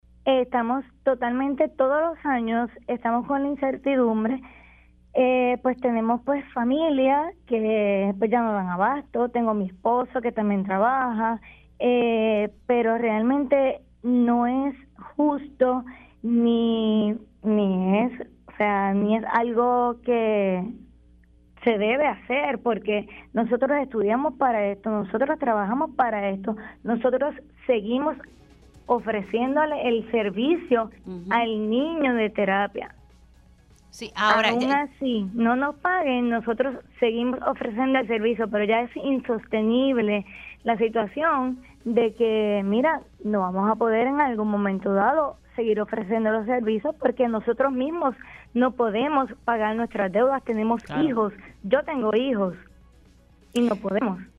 Tengo mi esposo que también trabaja, pero realmente no es justo ni es algo que se debe hacer porque nosotros estudiamos para estamos, nosotros trabajamos para esto, nosotros seguimos ofreciéndole el servicio al niño de terapia“, indicó en entrevista para Pega’os en la Mañana.